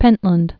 (pĕntlənd)